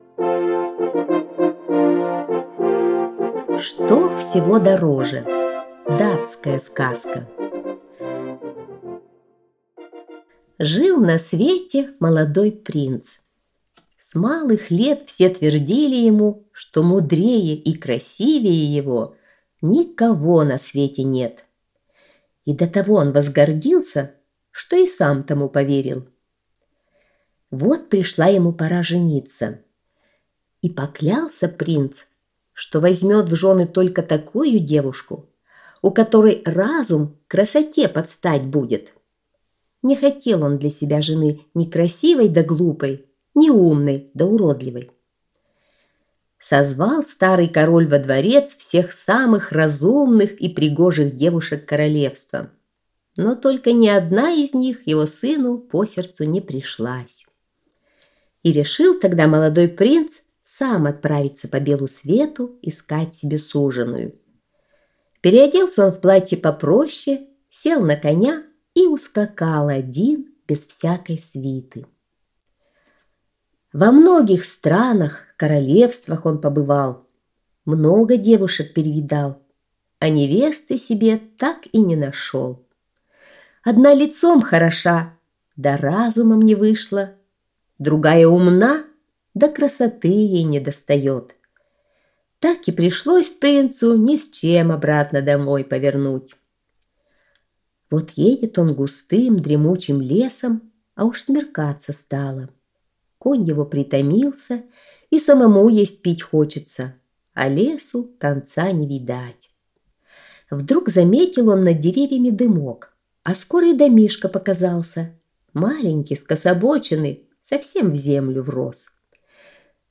Что всего дороже - датская аудиосказка - слушать онлайн